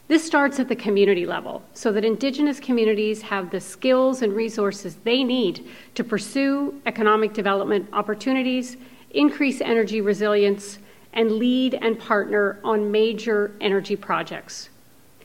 The Province of Ontario announced a major increase to the Indigenous Energy Support Program on Tuesday morning.